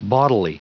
Prononciation du mot bawdily en anglais (fichier audio)
Prononciation du mot : bawdily